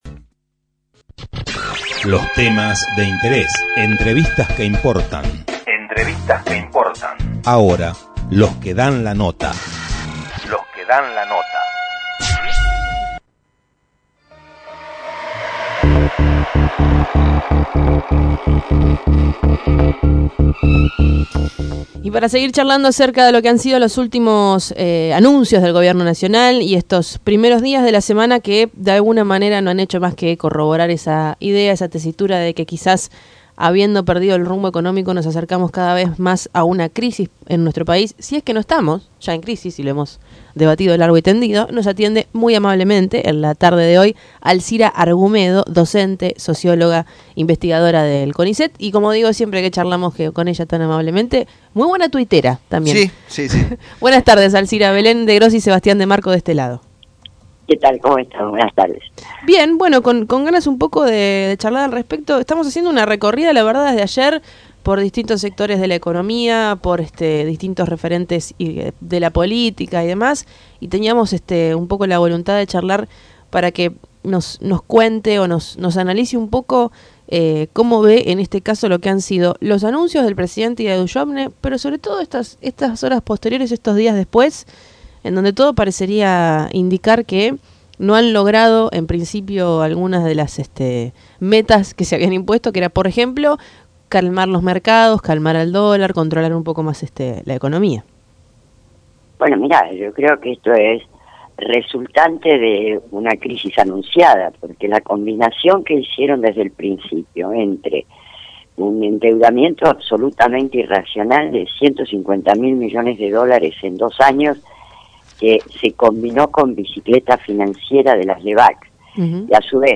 Tren Urbano dialogó con la socióloga y ex diputada nacional Alcira Argumedo sobre las políticas económicas del gobierno de Cambiemos.